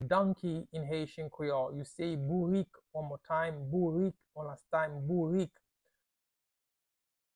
Pronunciation:
How-to-say-Donkey-in-Haitian-Creole-Bourik-pronunciation-by-a-Haitian-teacher.mp3